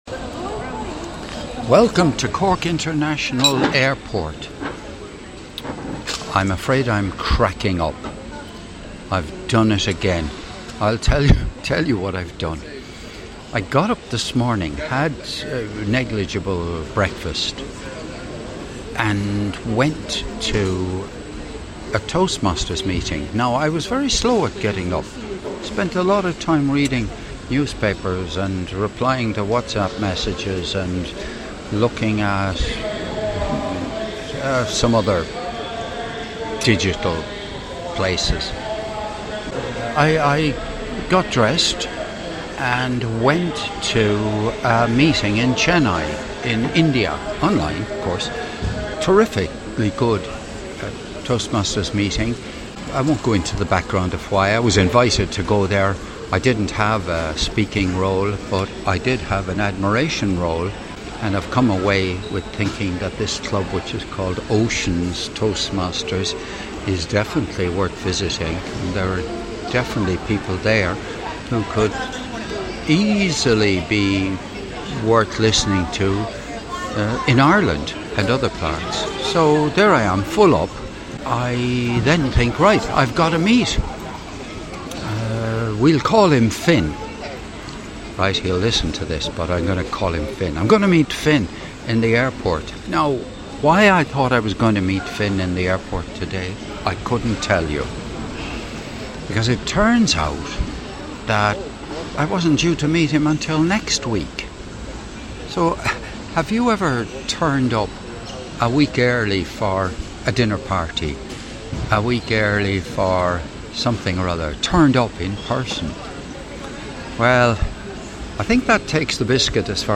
This audio was recorded in Cork International Airport on the morning of Saturday sixth of September 2025